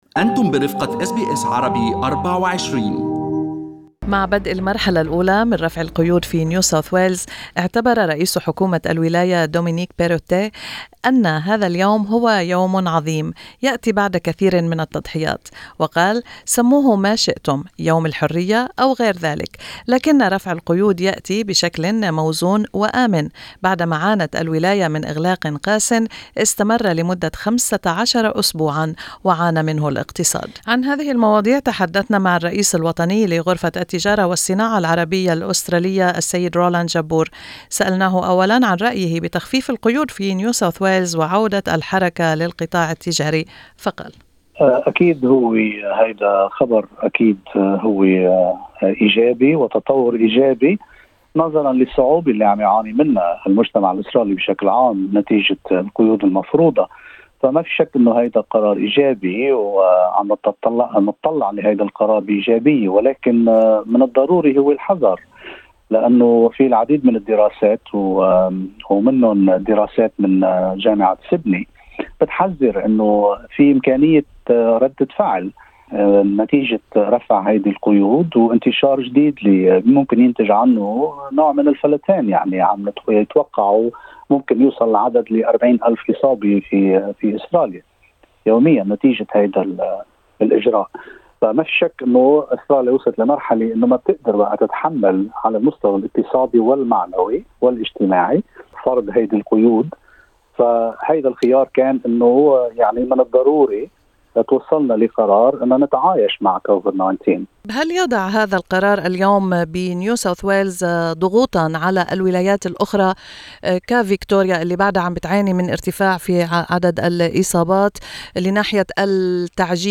في حديث مع SBS Arabic24 إن تخفيف القيود في سيدني هو تطور إيجابي.